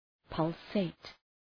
{‘pʌlseıt}
pulsate.mp3